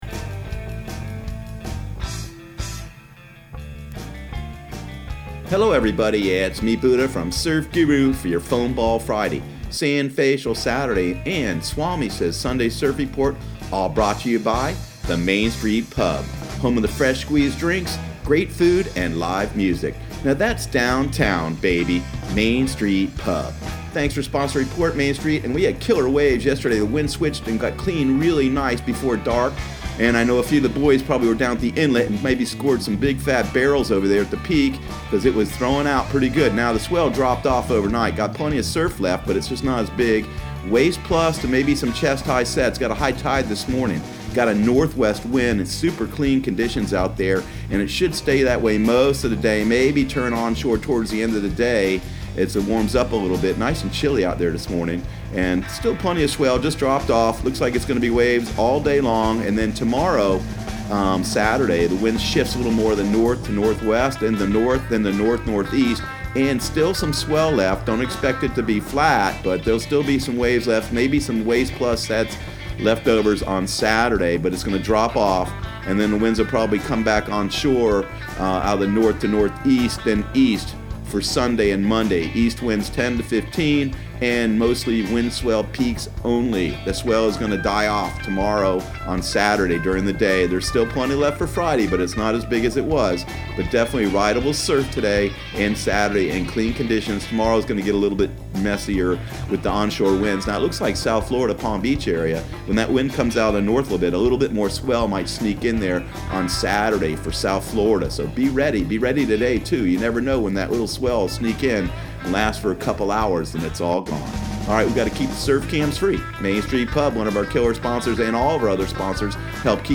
Surf Guru Surf Report and Forecast 03/22/2019 Audio surf report and surf forecast on March 22 for Central Florida and the Southeast.